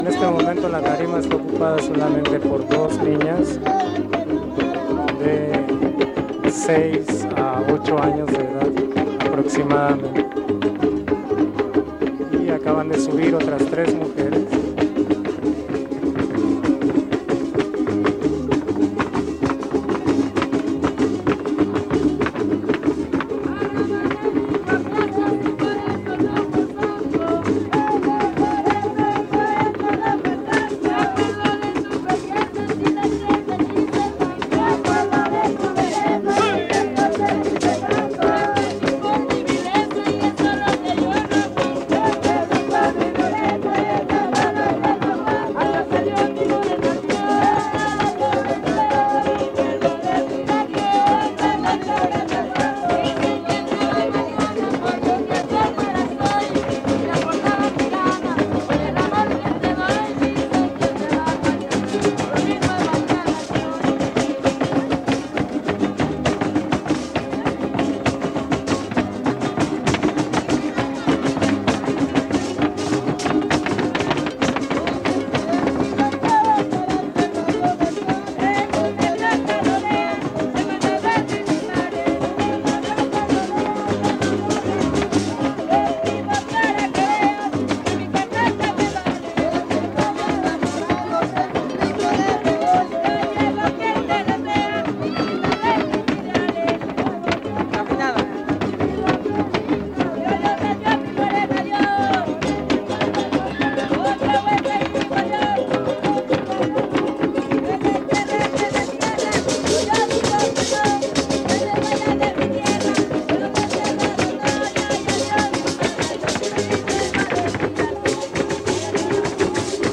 Encuentro de son y huapango